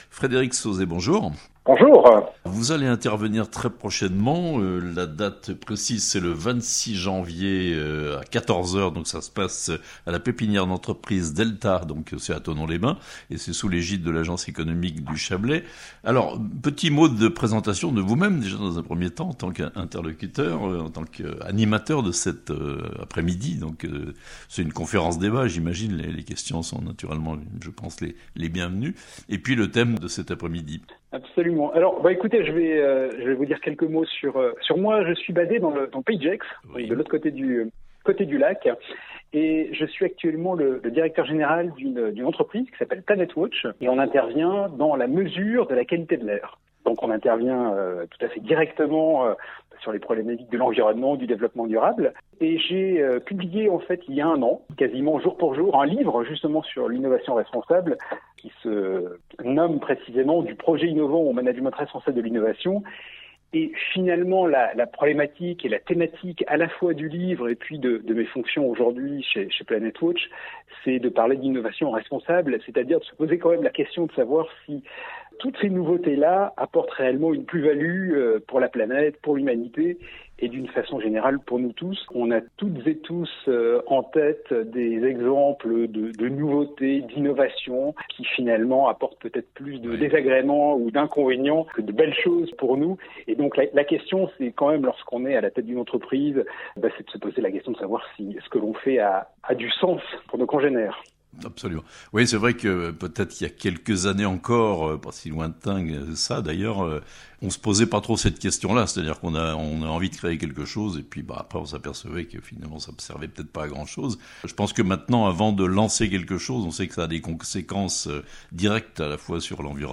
L'innovation responsable, thème d'une conférence-débat organisée par l'Agence Economique du Chablais (interview)